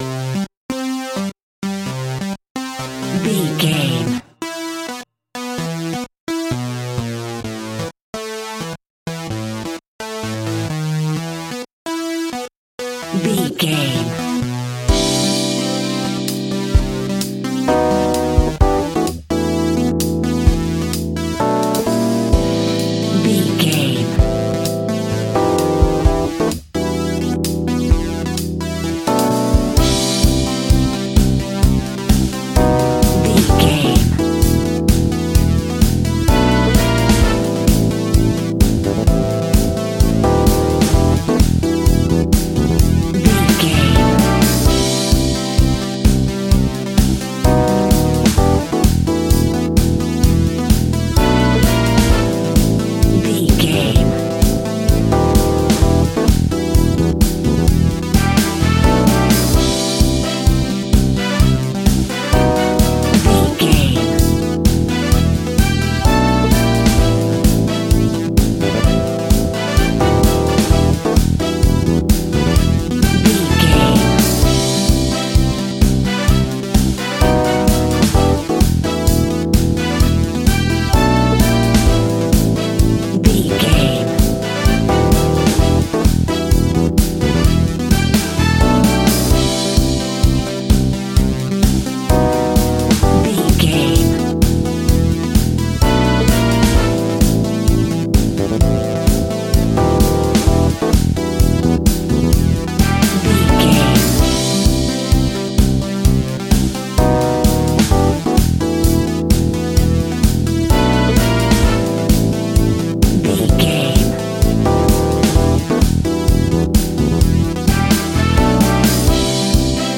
Ionian/Major
energetic
uplifting
hypnotic
synthesiser
electric piano
drums
deep house
groovy
uptempo
electronic drums
synth lead
synth bass